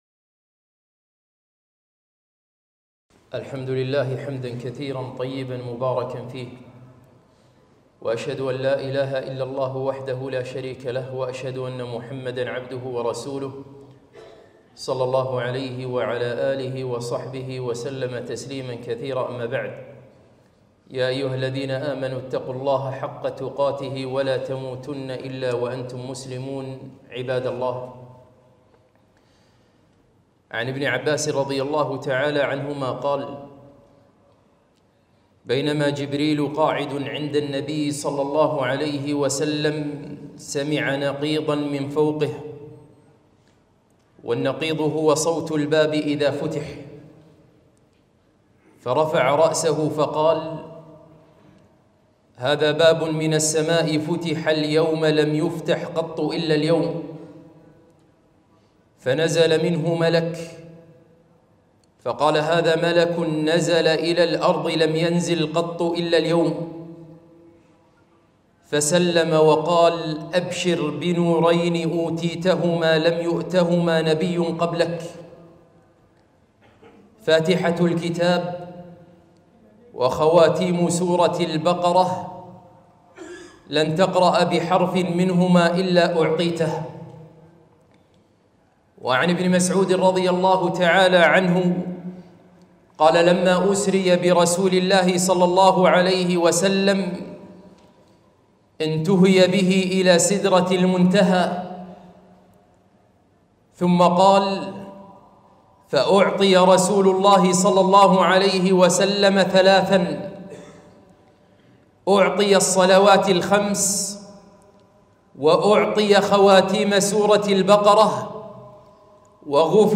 خطبة - خواتيم سورة البقرة